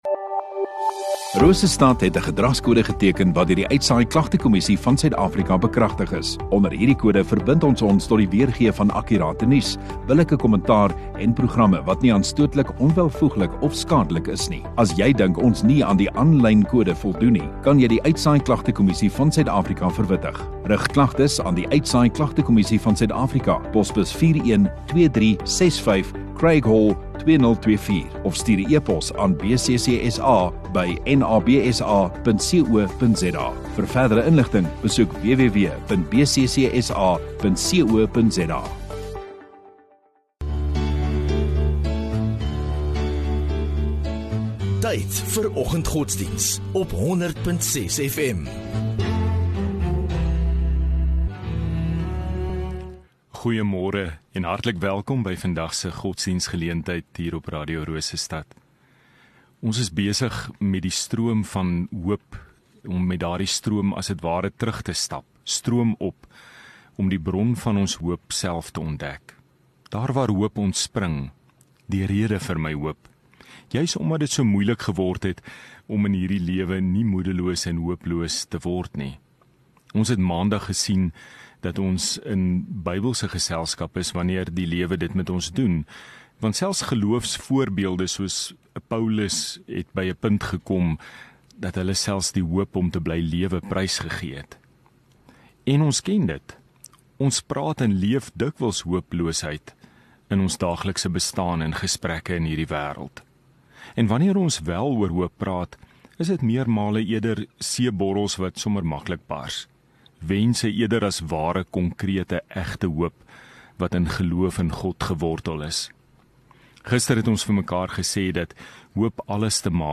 3 Dec Woensdag Oggenddiens